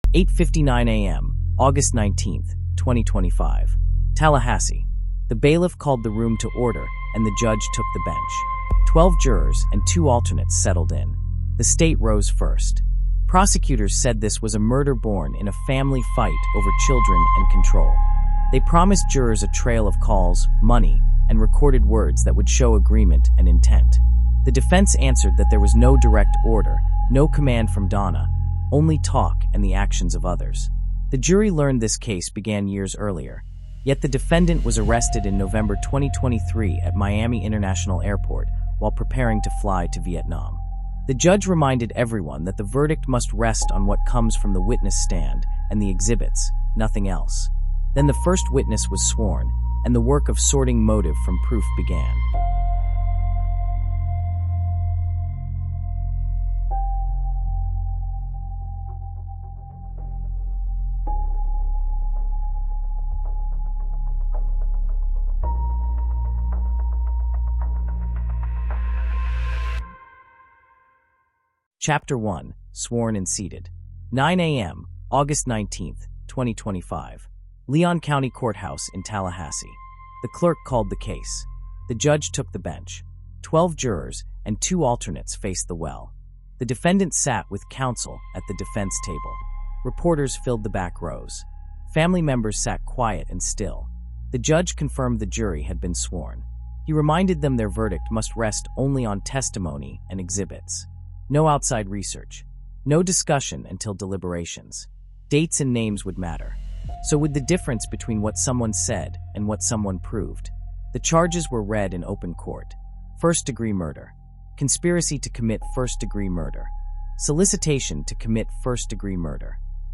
Presented in a clear, third-person documentary style, we dissect the sworn records of the trial day by day.